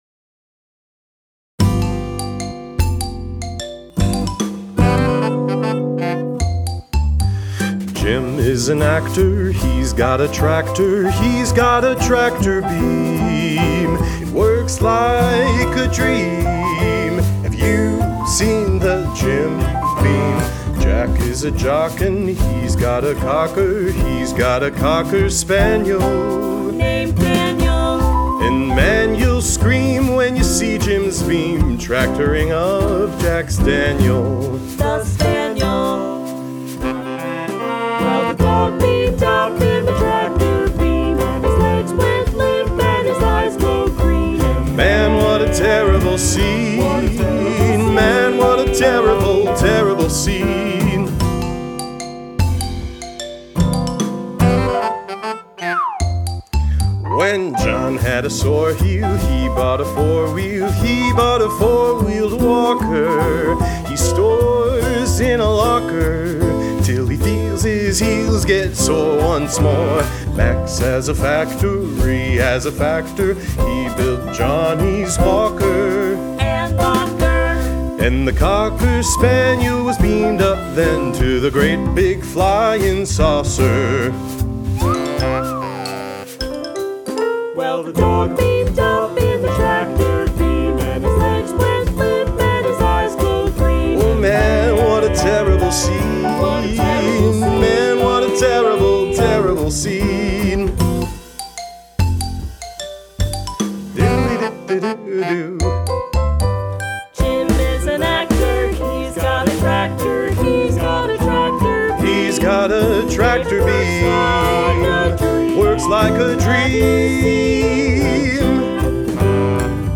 lead vocals
additional vocals